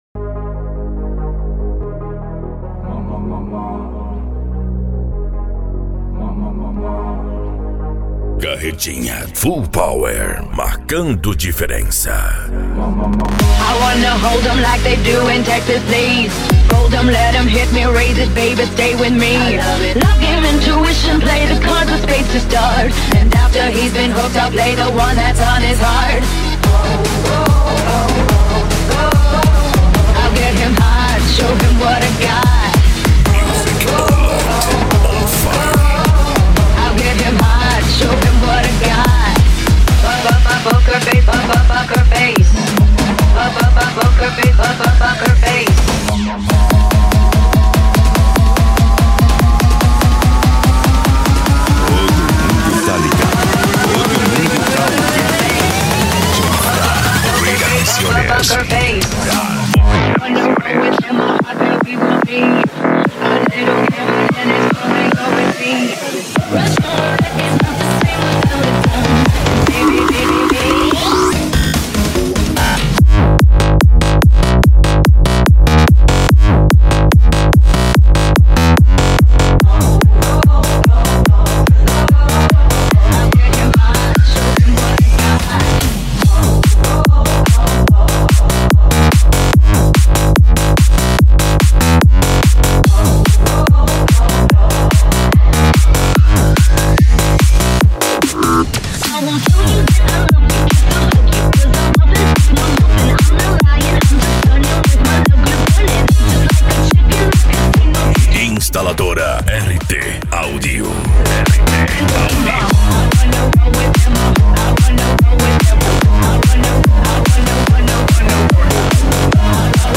Bass
Remix
Musica Electronica